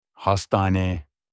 معنی و تلفظ “بیمارستان” به ترکی استانبولی: Hastane
hospital-in-turkish.mp3